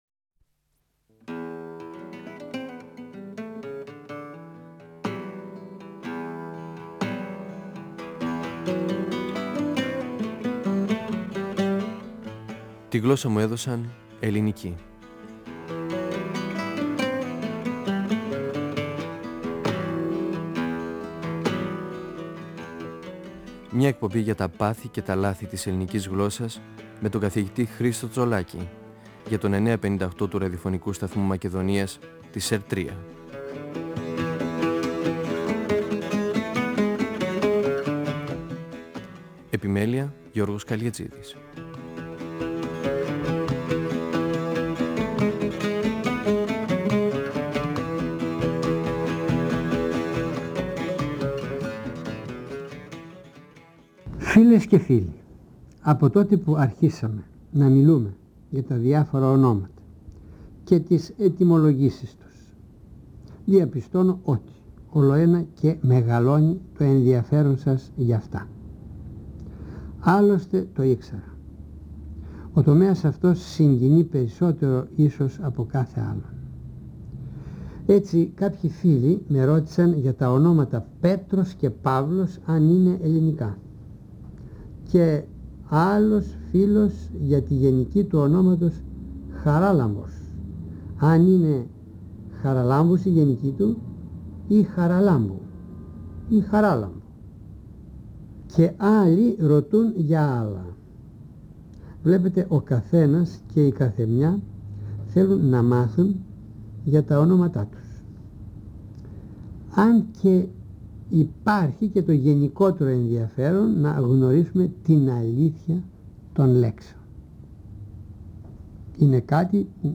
Ο γλωσσολόγος Χρίστος Τσολάκης (1935–2012) μιλά για την ετυμολογική ανάλυση των ονομάτων: Πέτρος, Παύλος, Ανδρέας, Ιωνάς.